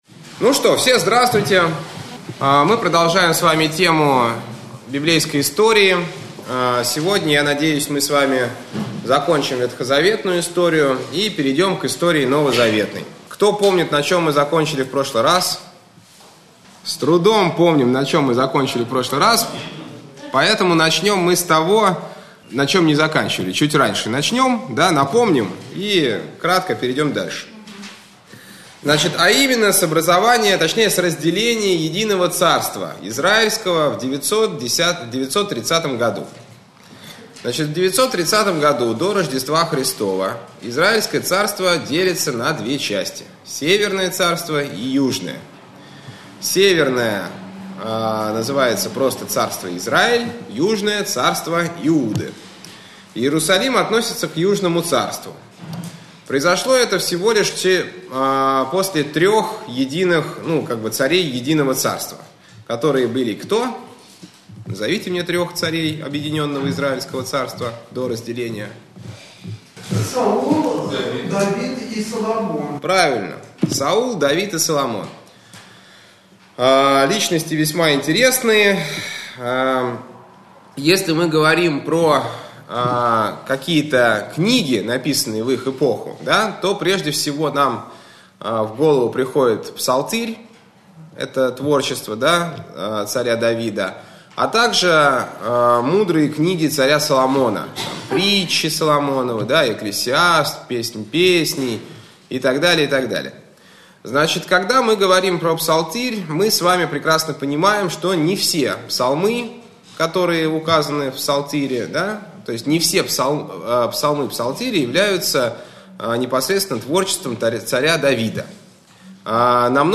Общедоступный православный лекторий